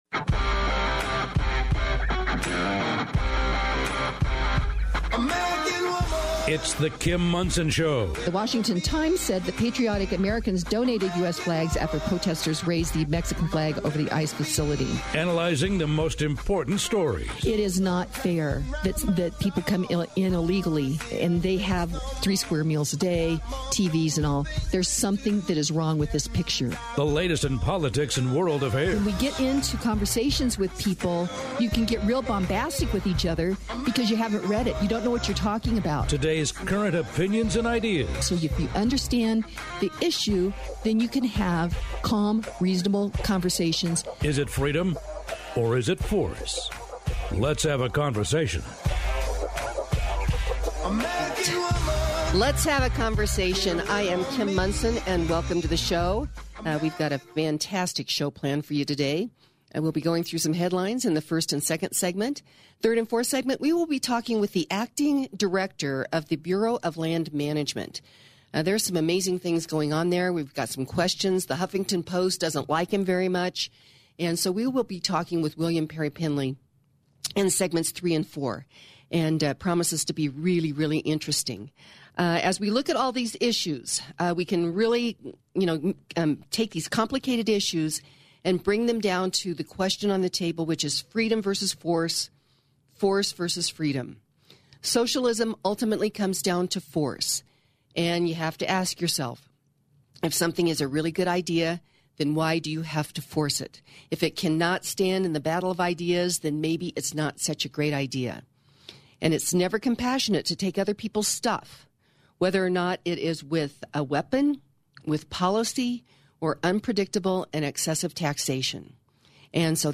A Conversation with William Perry Pendley on the Future of the Bureau of Land Management